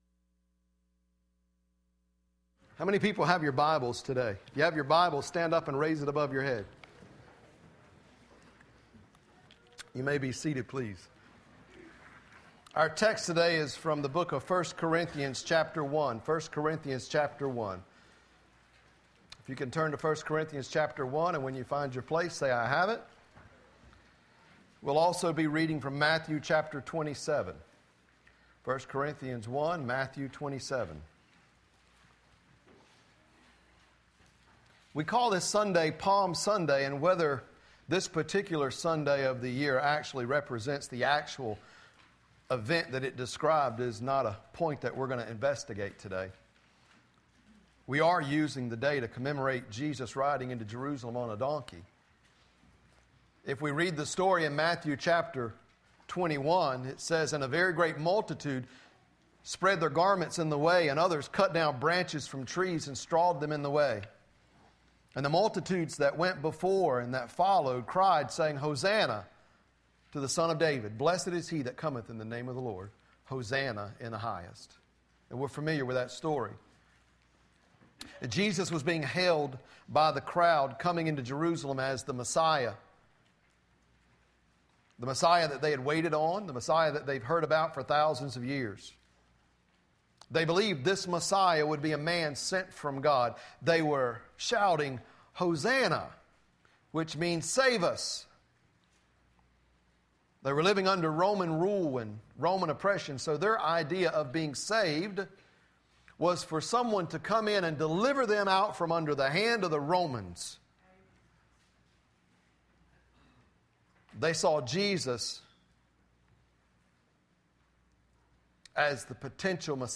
Listen to Sermons - Nazareth Community Church